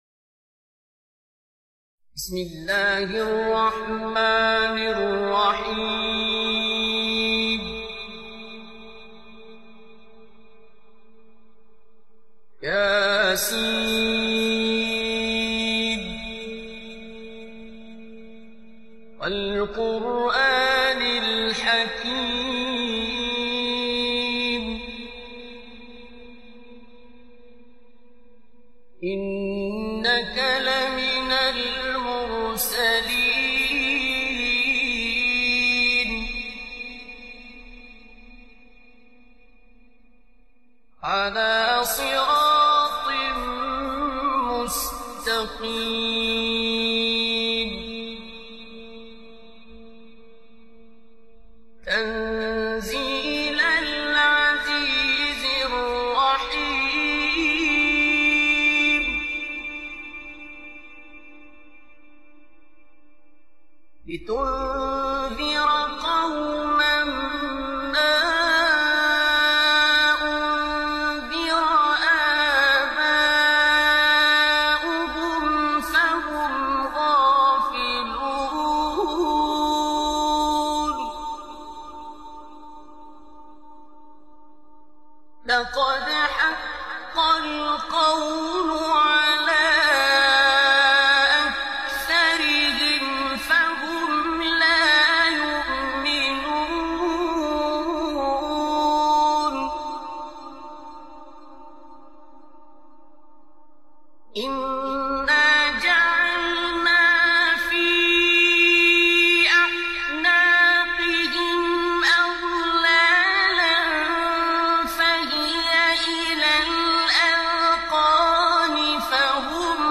Listen to Surah Yaseen in the soulful voice of Qari Abdul Basit, Egypt’s legendary reciter.
Enjoy the peaceful recitation of Surah Yaseen by Qari Abdul Basit in high-quality MP3 audio. His clear voice and lyrical recitation style make it easy to follow.
Surah-Yaseen-Tilawat-Beautiful-Quran-Recitation-by-Qari-Abdul-Basit-Full-HD-128.mp3